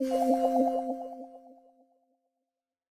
upgrade.ogg